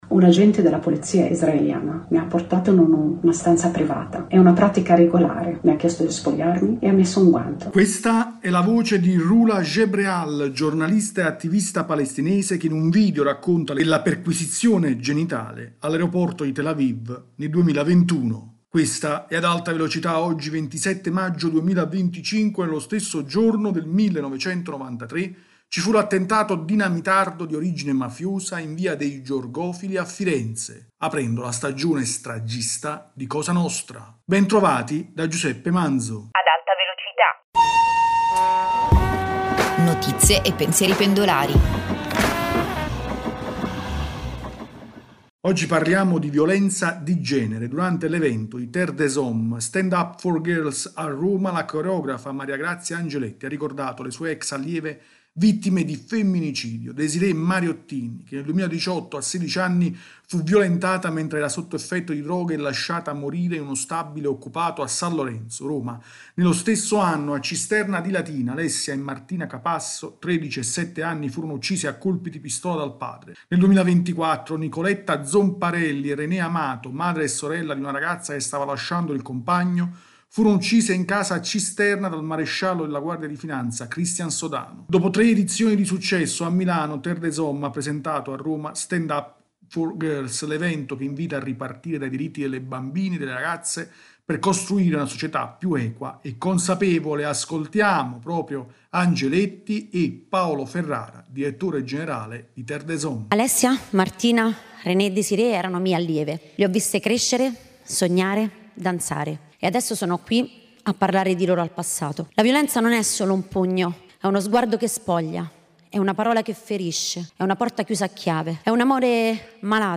[Questa è la voce di Rula Jebreal, giornalista e attivista palestinese, che in video racconta l’esperienza della perquisizione genitale all’aereoporto di Tel Aviv nel 2021.